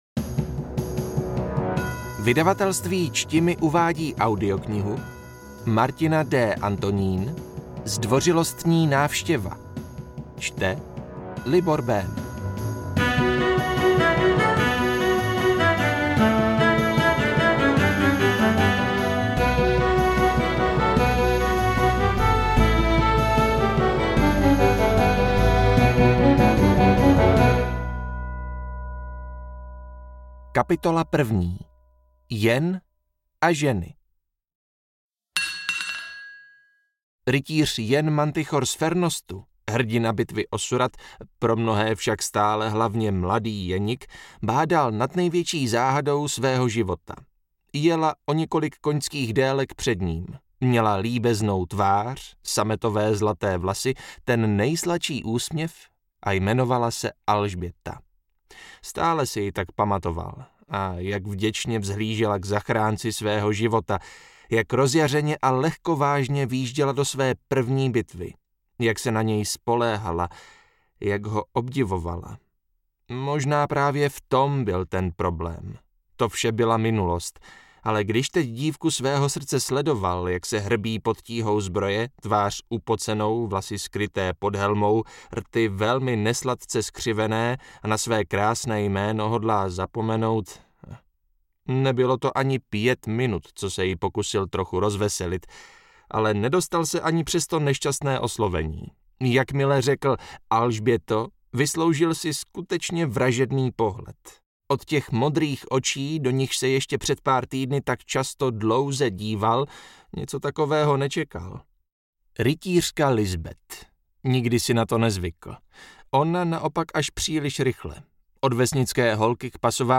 AudioKniha ke stažení, 7 x mp3, délka 4 hod. 6 min., velikost 227,1 MB, česky